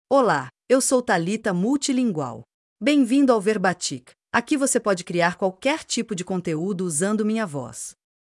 FemalePortuguese (Brazil)
Thalita MultilingualFemale Portuguese AI voice
Thalita Multilingual is a female AI voice for Portuguese (Brazil).
Voice sample
Listen to Thalita Multilingual's female Portuguese voice.